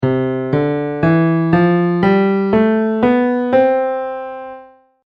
Even if you’ve never studied music you’re probably familiar with the concept of a “scale”, where a singer sings a series of notes going up in a row and then back down.
Major Scale
Major-Scale.mp3